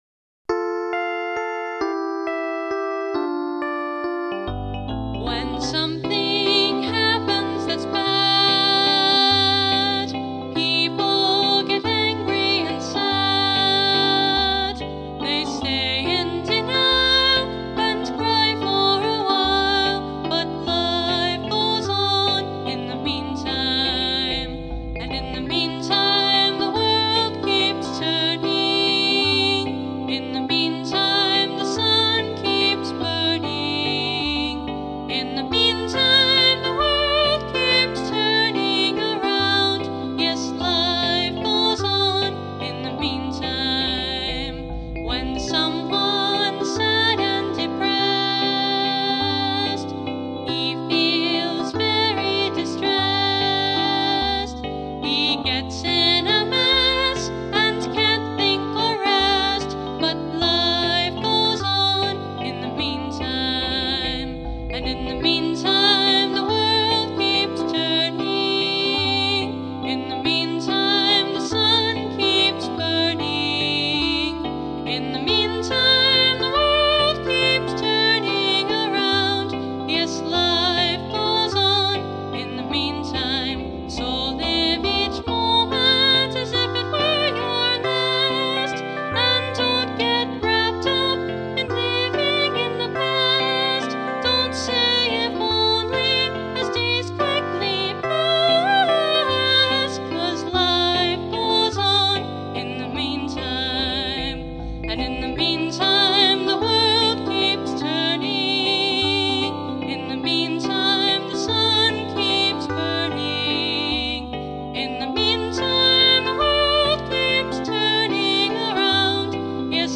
Original Songs
Not every song I wrote on piano was in 3/4 or waltz time, even though all of these are. 🙂